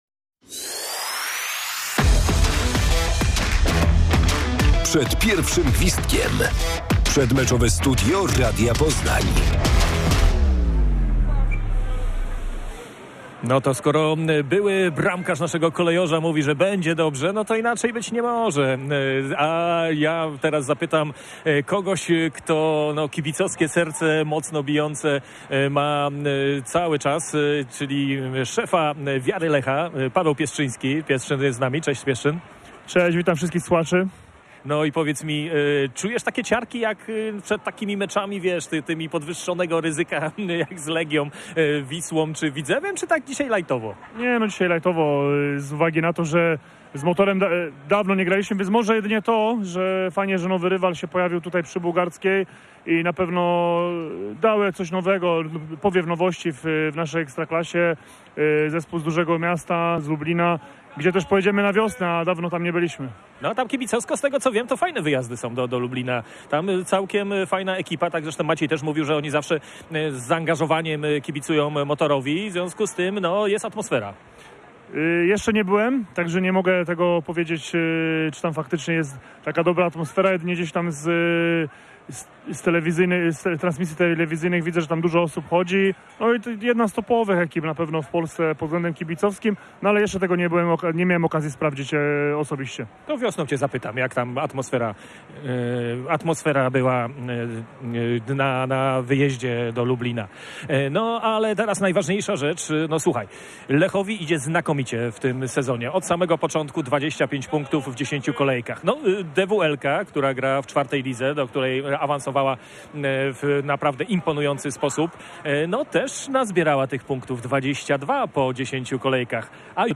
Studio Radia Poznań przed stadionem. Rozgrzaliśmy Kolejorza przed meczem z drużyną Motor Lublin
Radiowy namiot stanął tuż przed stadionem Lecha Poznań przy Bułgarskiej. Gościliśmy w nim ekspertów oraz Was - najwspanialszych kibiców, którzy przyszli dopingować Kolejorza podczas meczu z drużyną Motor Lublin.